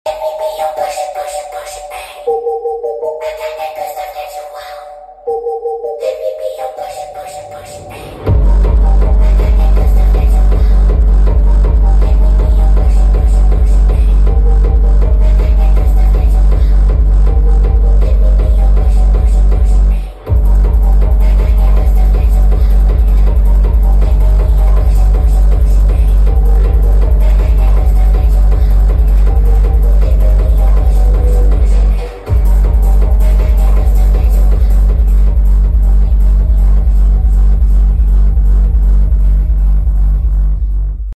Power Of Vibrations JblPartyBox 710